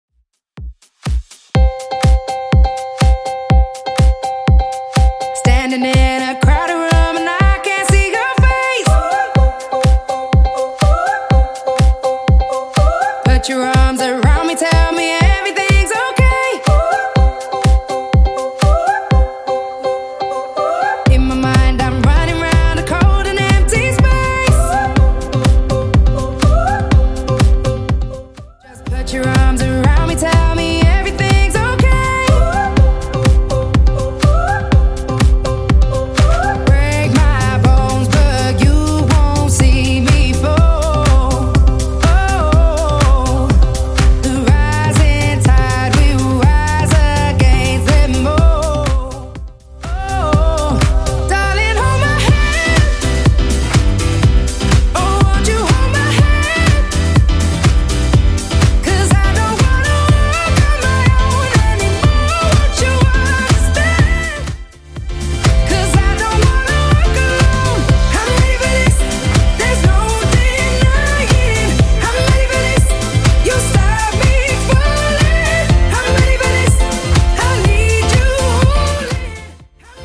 Genre: 80's Version: Clean